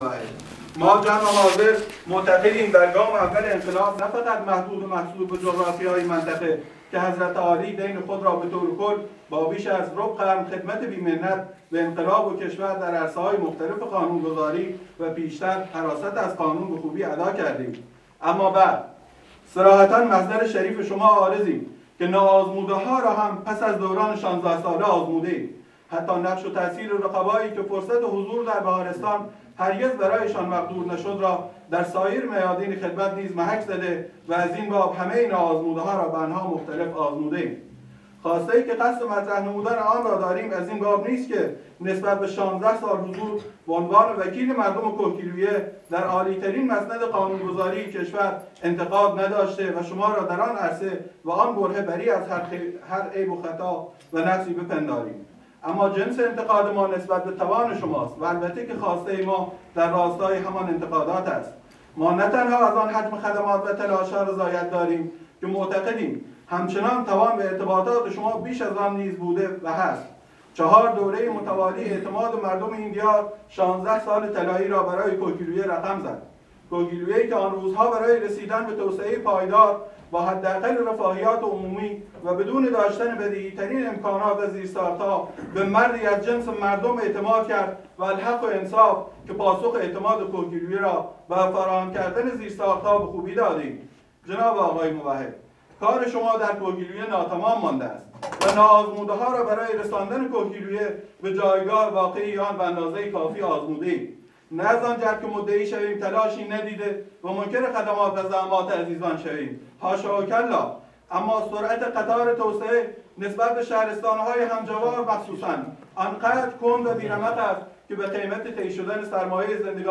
کبنا ؛ حجت الاسلام سید محمد موحد نماینده اسبق شهرستان‌های کهگیلویه، بهمئی، چرام و لنده امروز (جمعه 9 فروردین ماه 98) در نشست با جوانان کهگیلویه، بهمئی، چرام و لنده با اشاره به بیانیه ی گام دوم انقلاب، با بیان اینکه مقام معظم رهبری بر جوان گرایی تاکید داشتند و اظهار کرد: همیشه بنده به جوان گرایی معتقد بودم و باید از وجودشان بهره گیری شود.